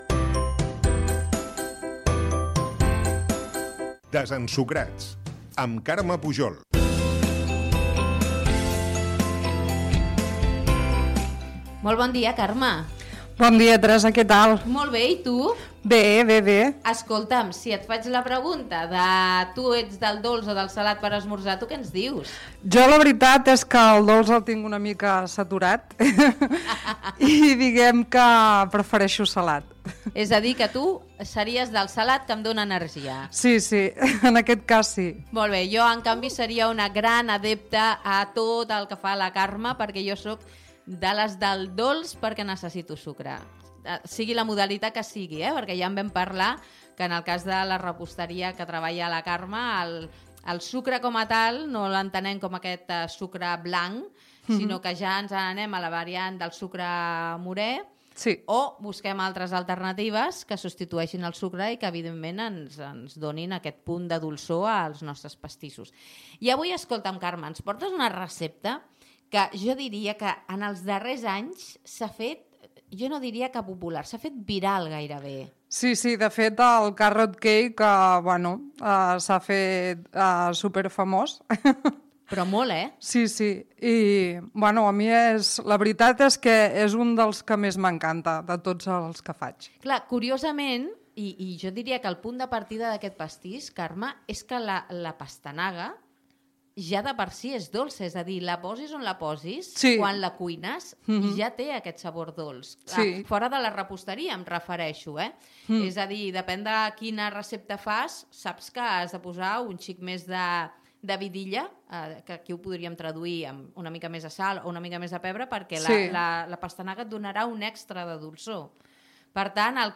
La recepta del pastís de pastanaga Gènere radiofònic Entreteniment